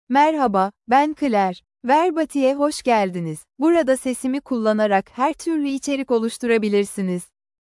ClaireFemale Turkish AI voice
Claire is a female AI voice for Turkish (Turkey).
Voice sample
Female
Claire delivers clear pronunciation with authentic Turkey Turkish intonation, making your content sound professionally produced.